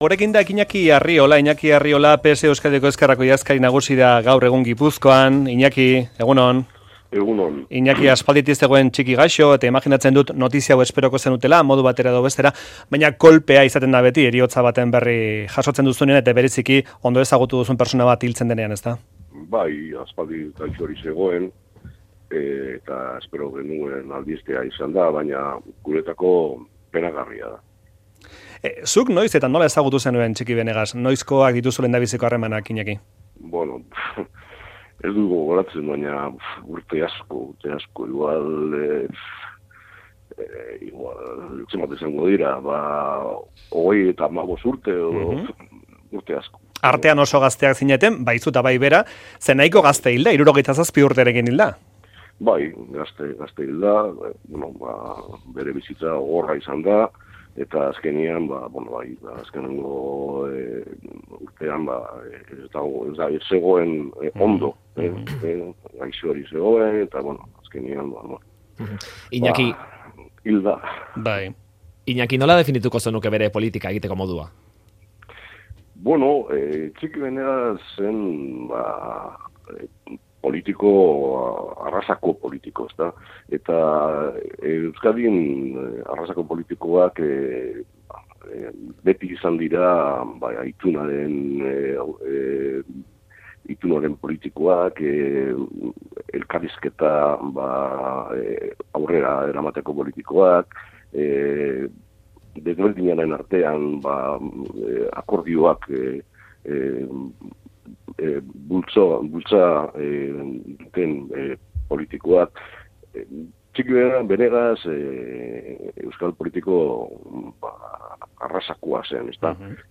Iñaki Arriola Gipuzkoako sozialisten idazkari nagusiarekin hitz egin dute Faktorian.